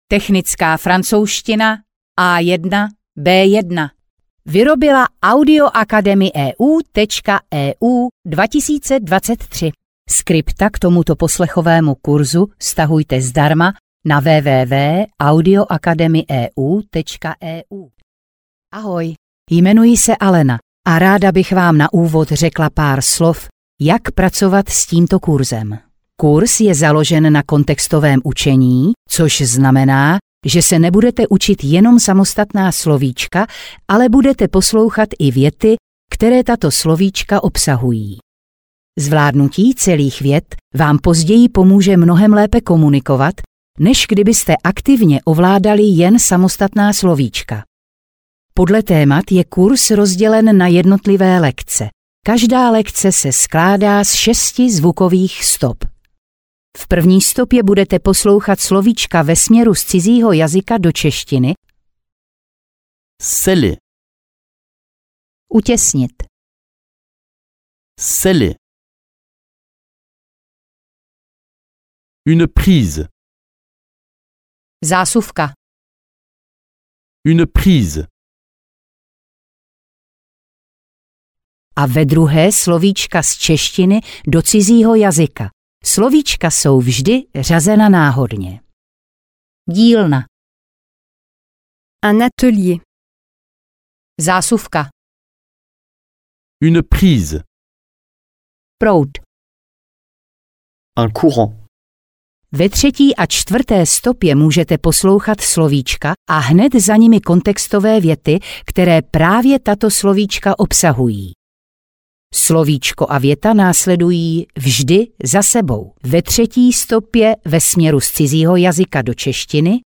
Technická francouzština A1–B1 audiokniha
Ukázka z knihy